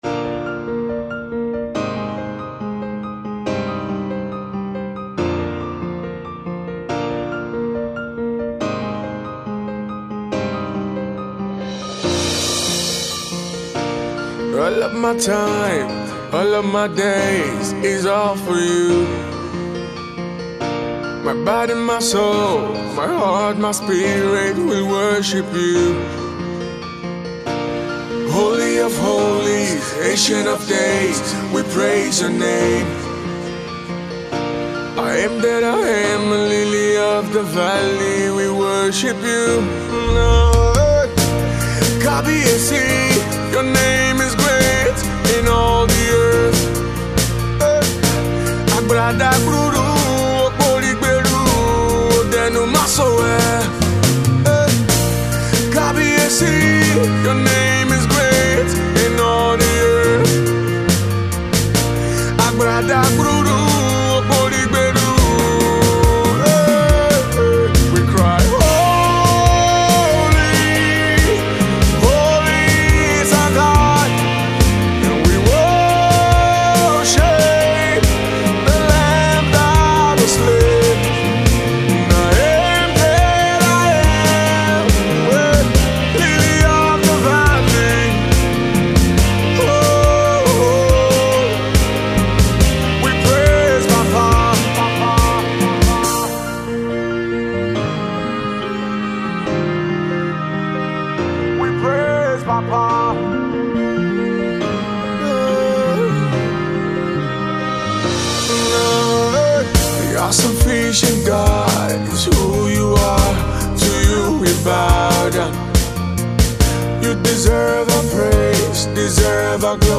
Here is a worship song from singer